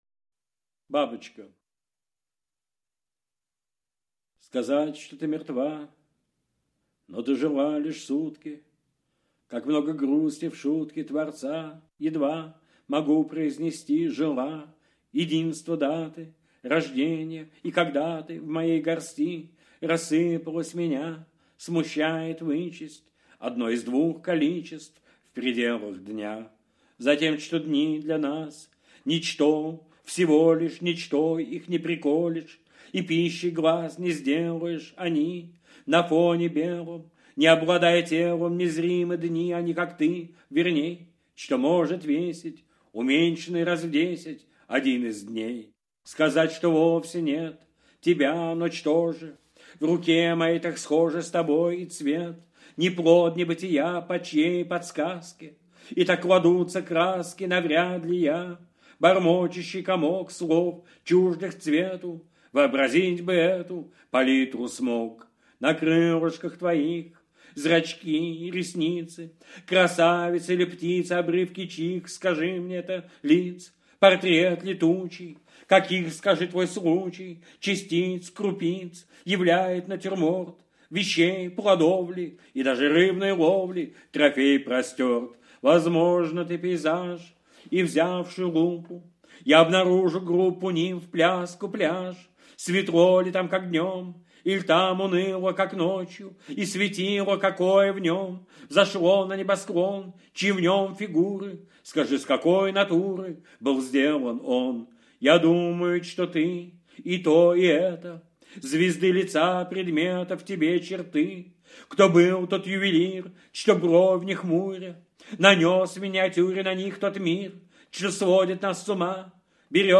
Brodskiy-Babochka-chitaet-avtor-stih-club-ru.mp3